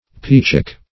Meaning of peachick. peachick synonyms, pronunciation, spelling and more from Free Dictionary.
Search Result for " peachick" : Wordnet 3.0 NOUN (1) 1. a young peafowl ; [syn: peachick , pea-chick ] The Collaborative International Dictionary of English v.0.48: Peachick \Pea"chick`\ (p[=e]"ch[i^]k`), n. (Zool.)